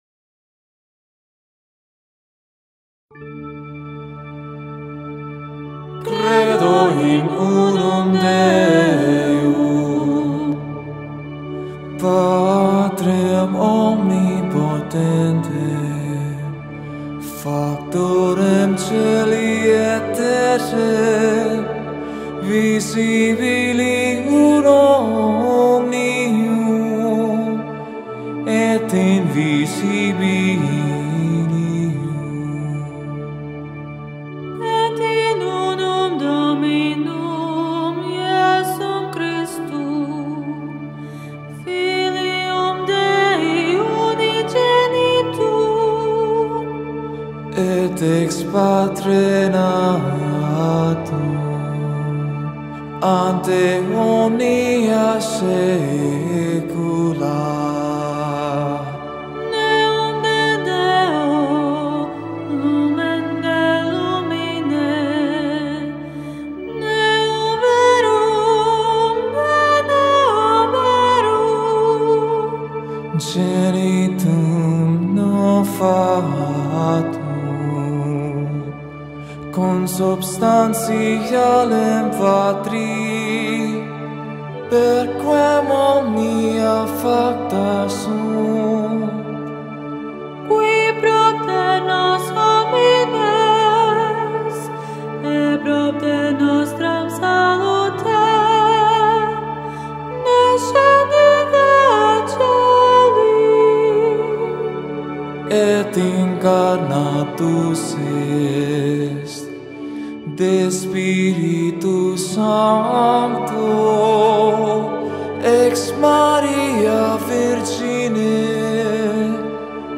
Explication du Credo (Wikipédia) Credo latin chanté